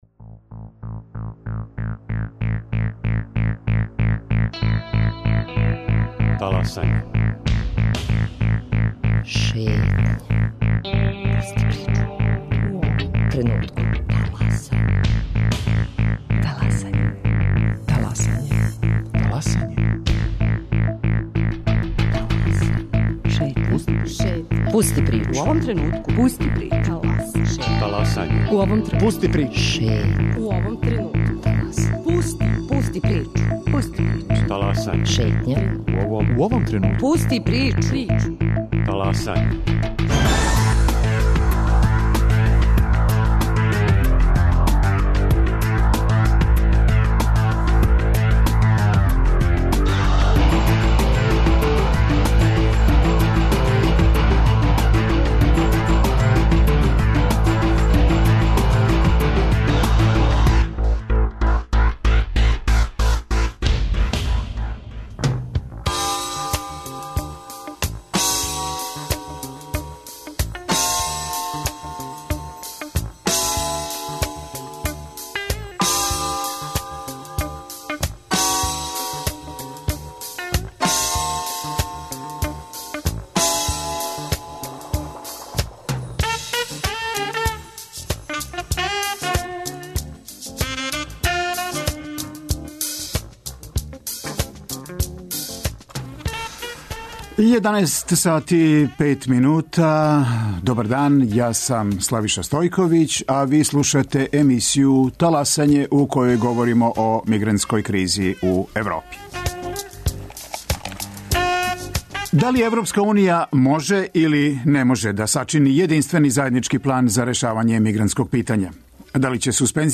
У емисију ће се укључити и дописницима из европских земаља.